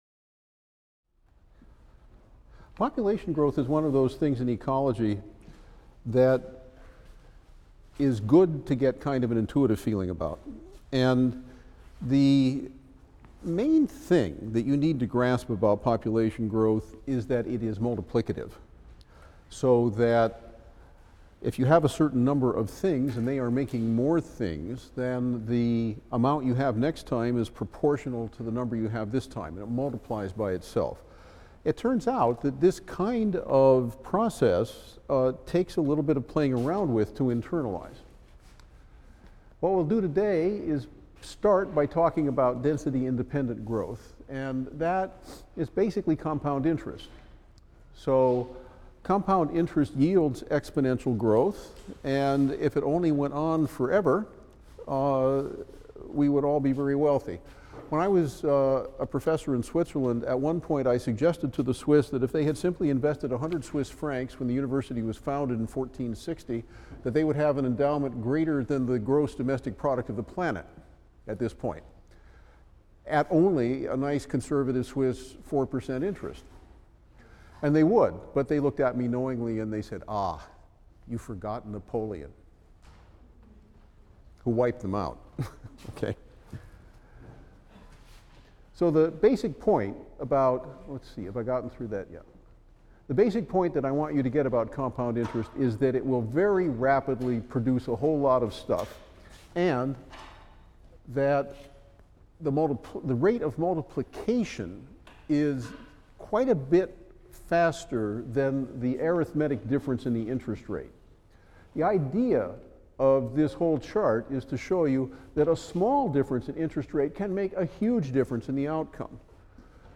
E&EB 122 - Lecture 26 - Population Growth: Density Effects | Open Yale Courses